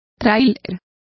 Complete with pronunciation of the translation of caravan.